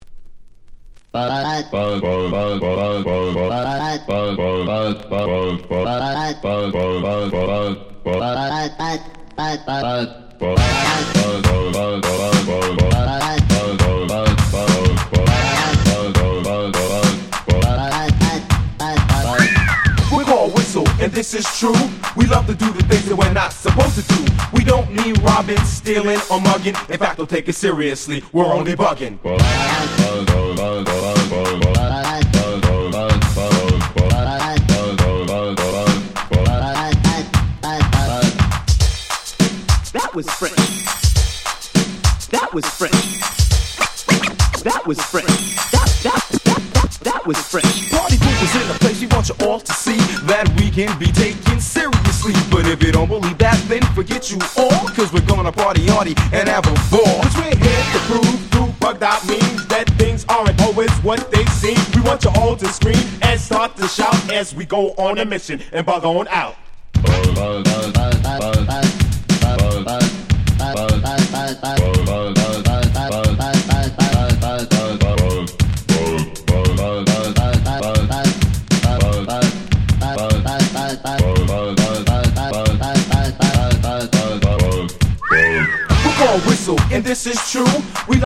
85’ Middle School/Electro Hip Hop Classic !!